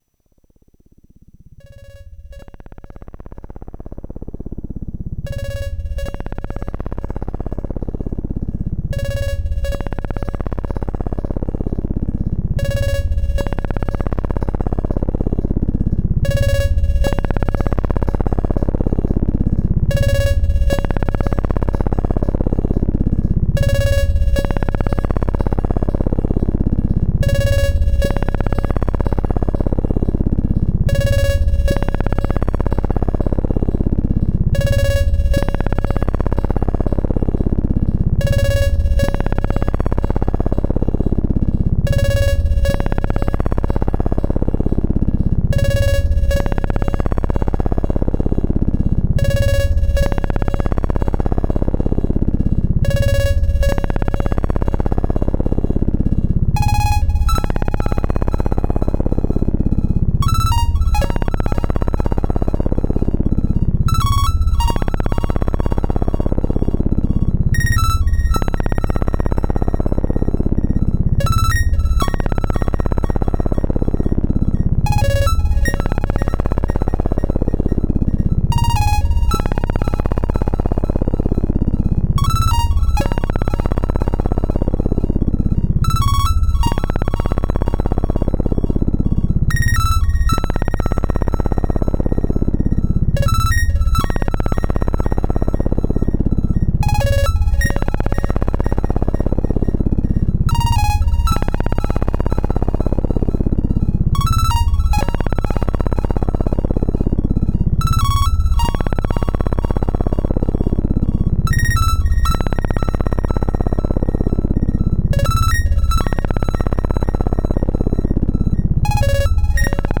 Enregistrement du A100 seul. C'est de la musique générative simple.
L'enveloppe contrôle non seulement le forme d'onde DDS mais aussi la SQR grâce au seuil de déclenchement de l'EF.
Le tempo du Sequencer et les LFO ne sont pas synchronisés. Ce qui génère une mélodie sur le SQR.
Ajout d'un écho par le Raspbeey/pd/Studio-1
• Format : ogg (stéréo)